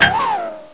1 channel
TANKHITR.WAV